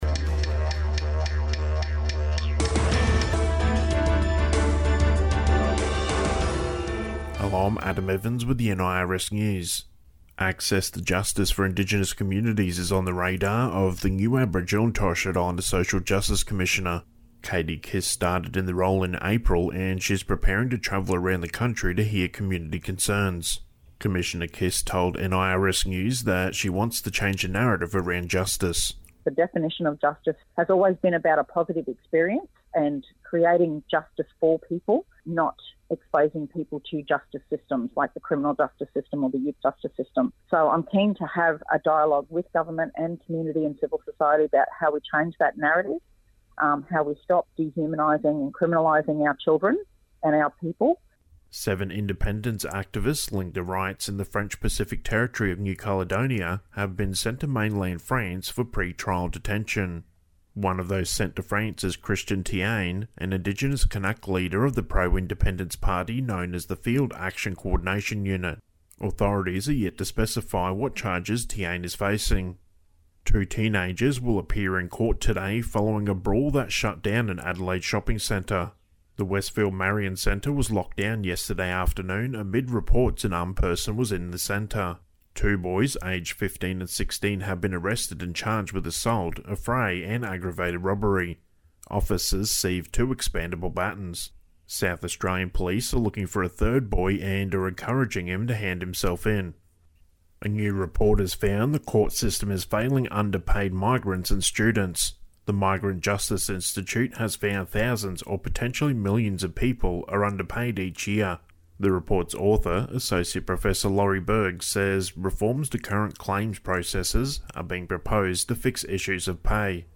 The Honourable Lance McCallum MP, Minister for Employment and Small Businesses, Training and Skills Development and Youth Justice, talking about $7 million ‘life changing’ job creation program for First Nations Queenslanders.